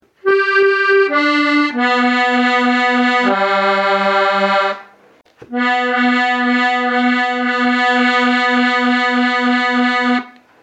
Hallo, Bei meiner neuen Harmonika GCFB hört sich der zweite Ton unter dem markierten Knopf in der ersten Reihe auf Druck wie in der mp3 an.
Habt Ihr schon einmal so einen eiernden Ton gehabt?